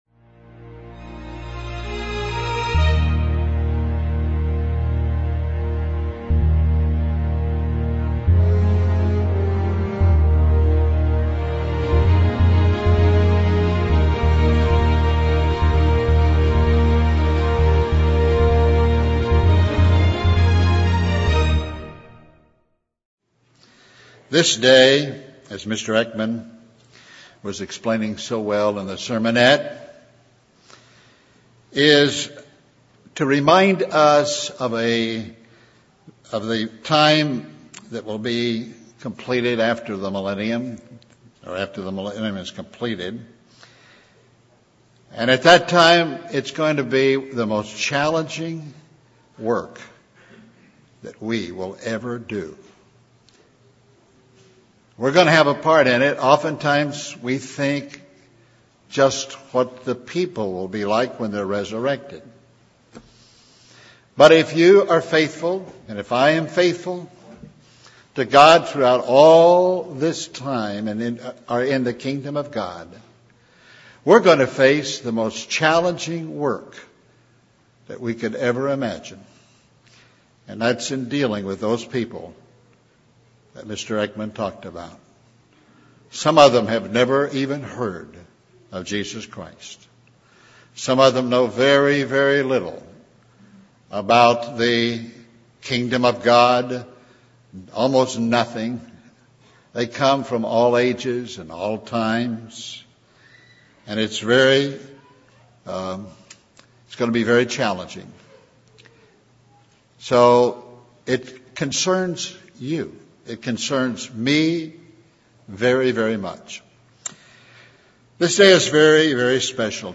2010 Feast of Tabernacles sermon from Anchorage, Alaska.